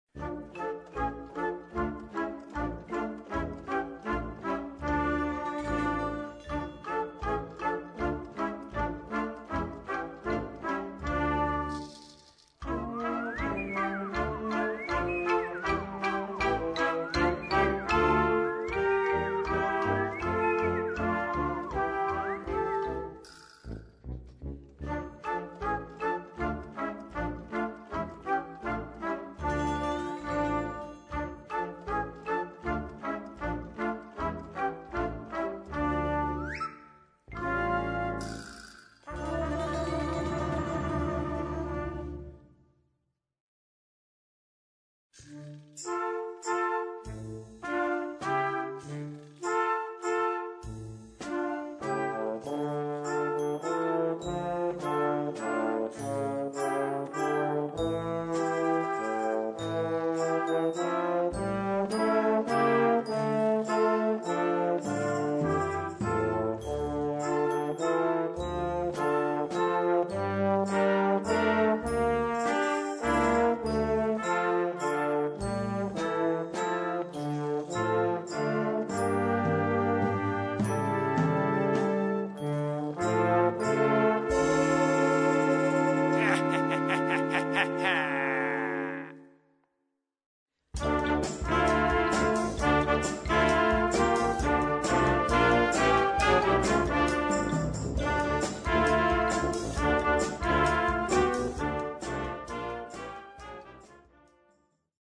Gattung: Jugendwerk
A4 Besetzung: Blasorchester PDF